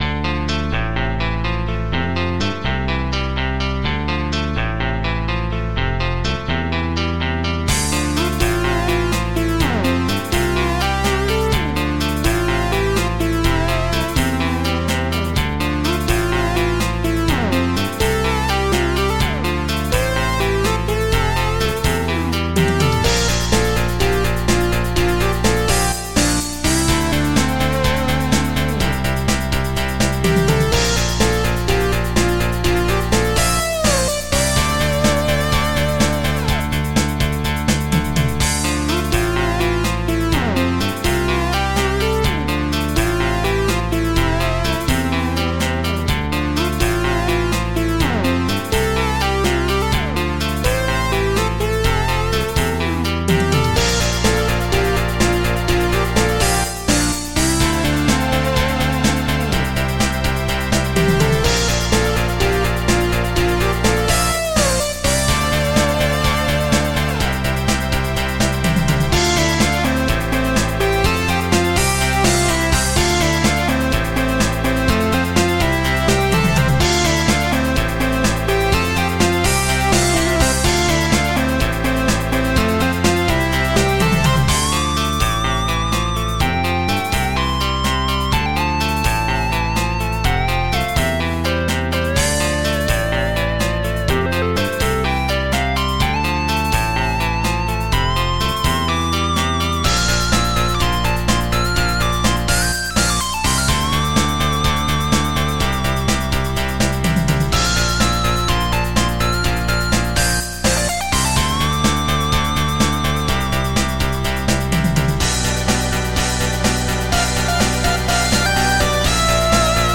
alternative rock greatest hits!